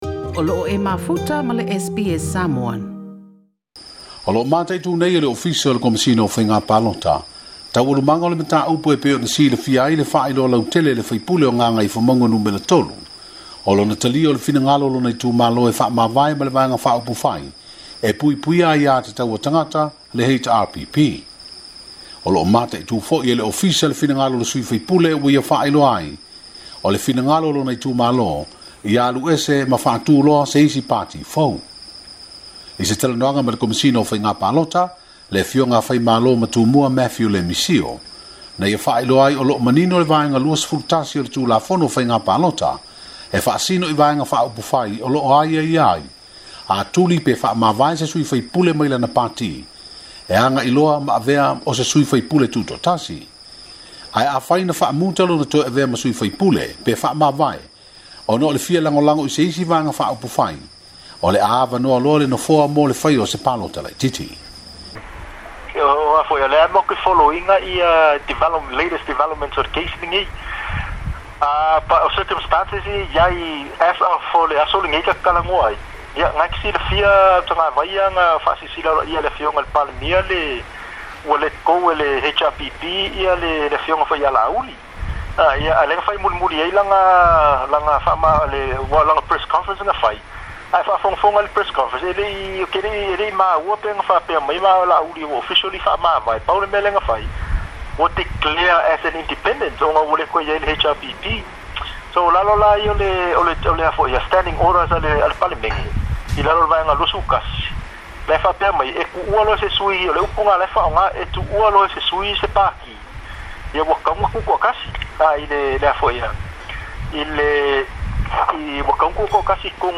I se talanoaga ma le Komesina o faigapalota